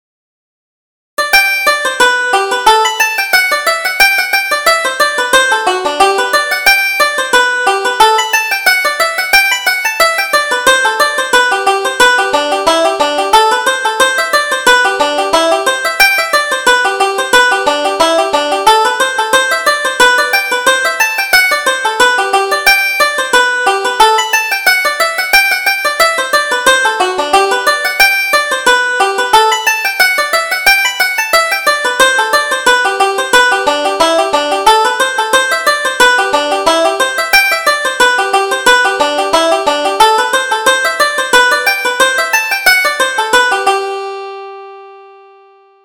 Reel: Molly Malone